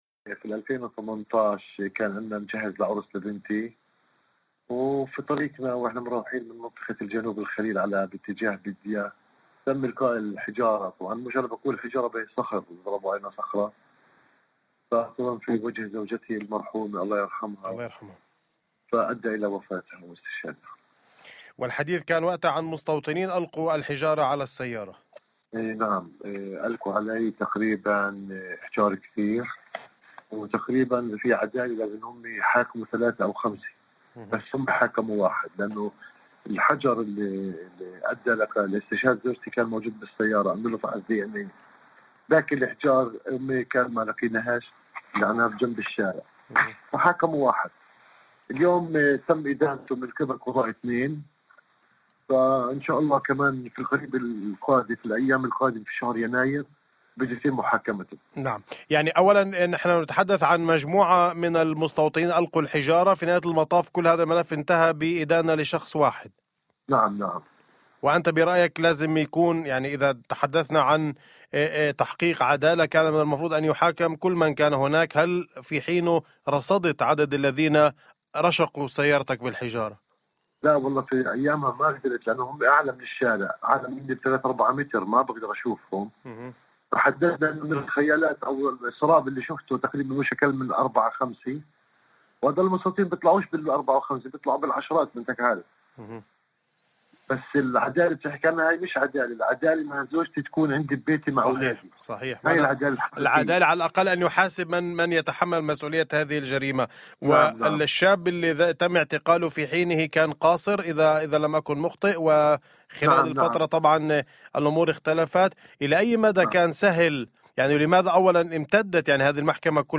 في حديث لإذاعة الشمس ضمن برنامج " أول خبر "